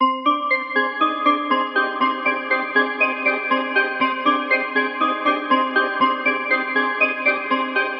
LOOP 2 120bpm
Tag: 129 bpm Trap Loops Synth Loops 1.35 MB wav Key : Unknown